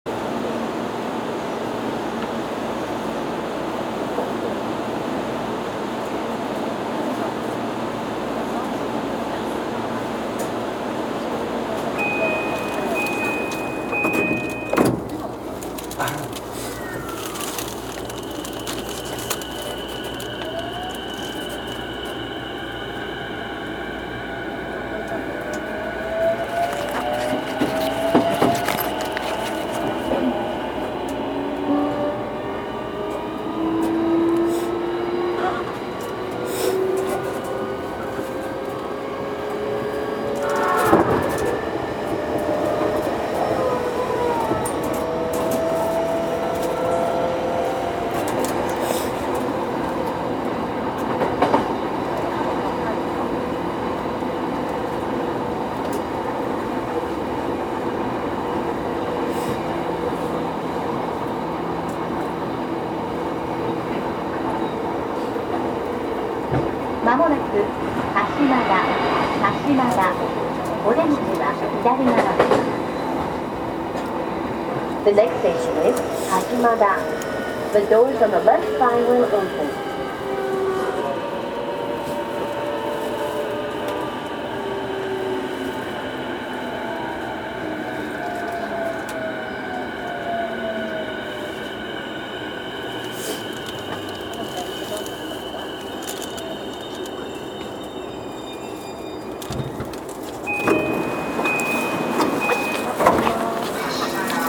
走行音
録音区間：平間～鹿島田(お持ち帰り)